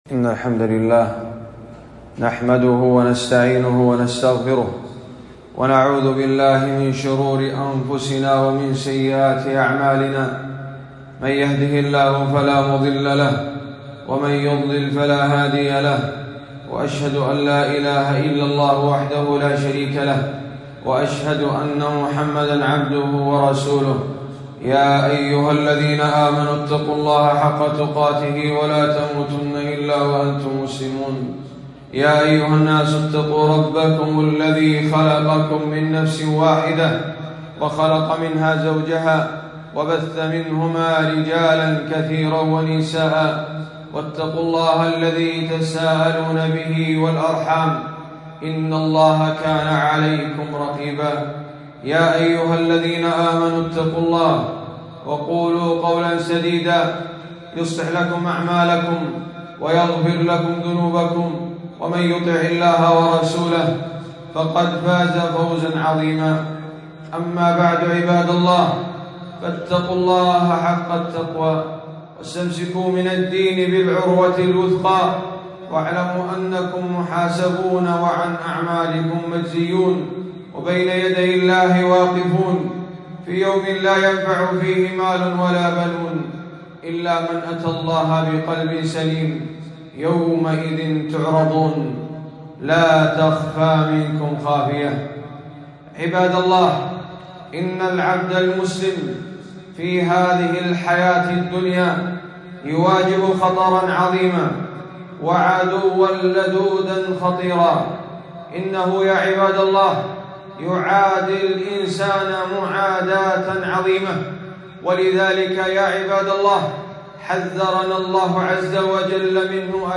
خطبة - الحروز الشرعية من عدو البشرية